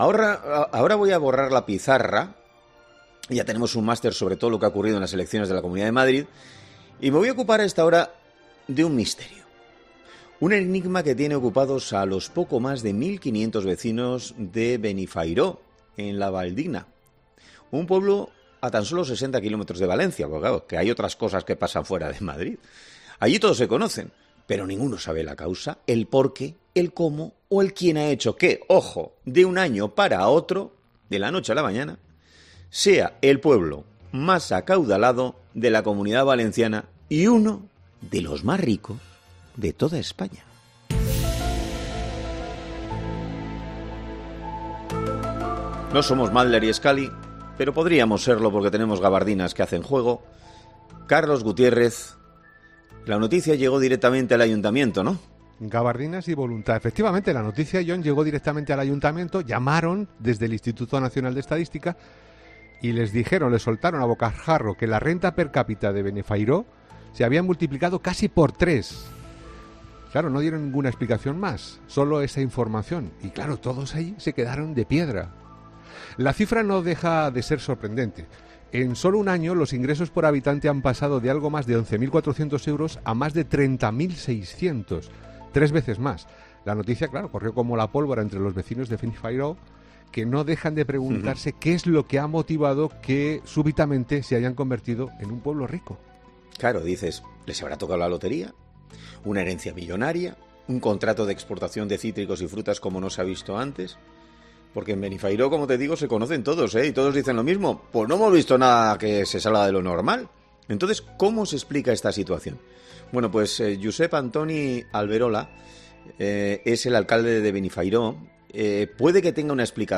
Este martes ha sido entrevistado en 'Herrera en COPE' el alcalde del municipio, Josep Antoni Alberola, que ha dicho que la razón se debe a algún movimiento imputable a alguna de las empresas del polígono industrial, que es "muy potente".